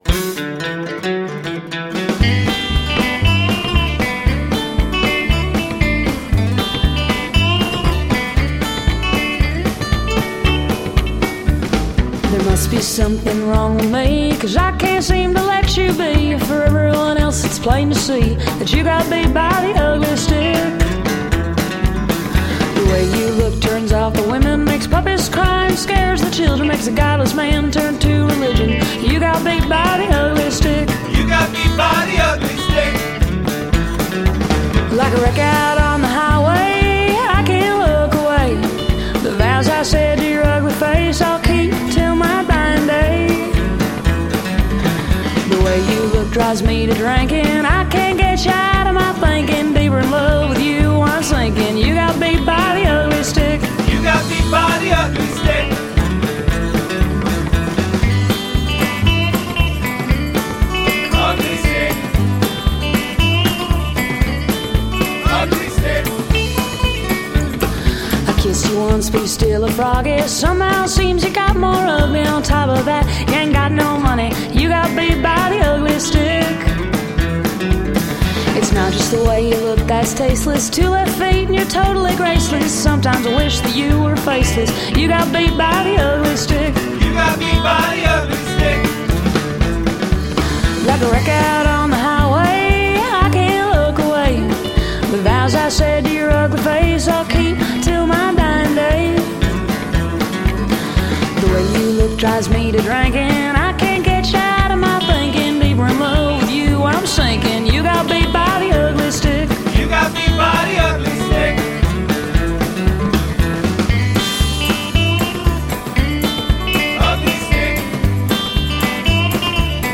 Country, rockabilly, western swing and lounge.
Tagged as: Alt Rock, Folk-Rock, Country